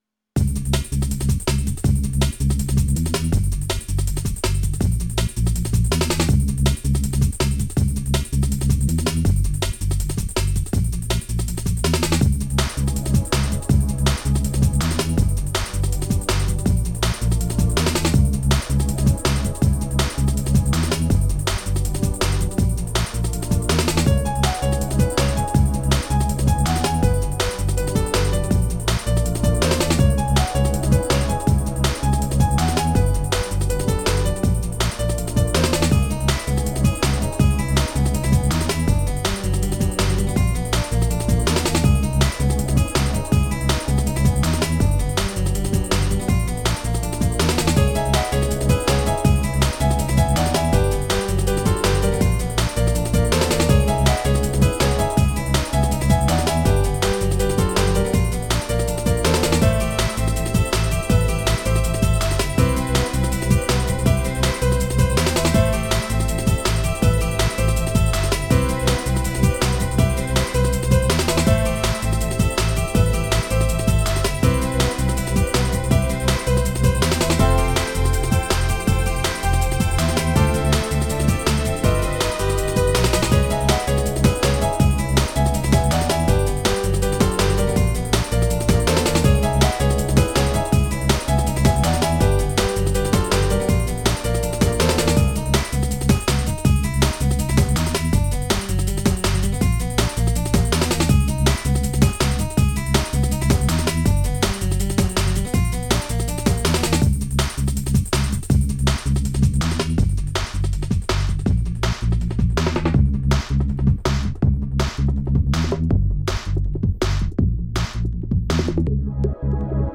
Jungle